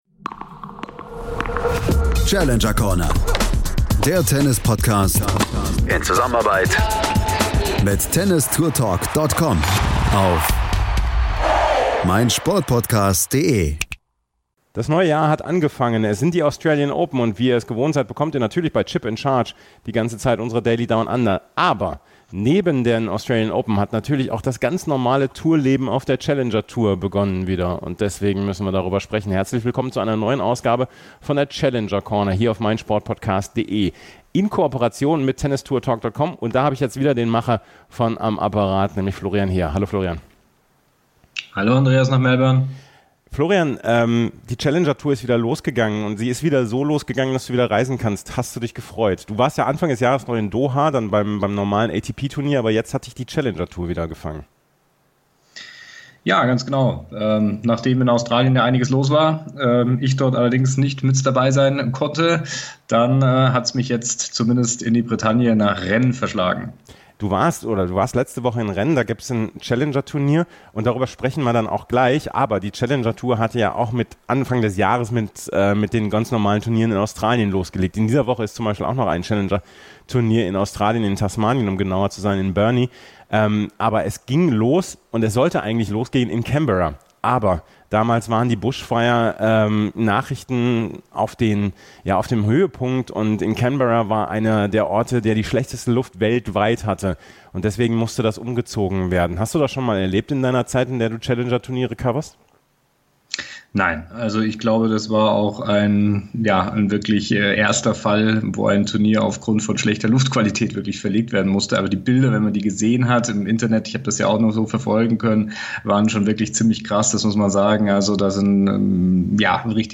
Mitgebracht hat er auch einige Interviews mit Spielern.